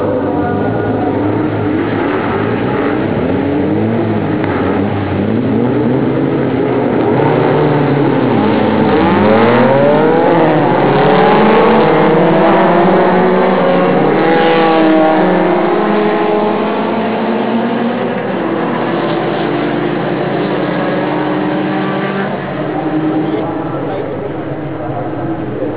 スタートの轟音は